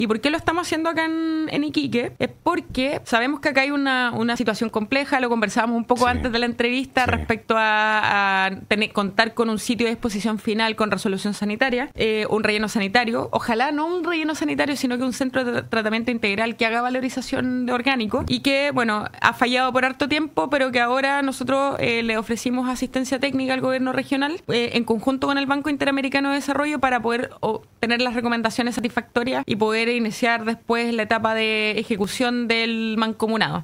En entrevista con Radio Paulina, Perales explicó que la Subdere, en coordinación con el Gobierno Regional de Tarapacá y el Banco Interamericano de Desarrollo (BID), está enfocada en obtener la Recomendación Satisfactoria (RS) del proyecto, requisito fundamental para asegurar su financiamiento e implementación.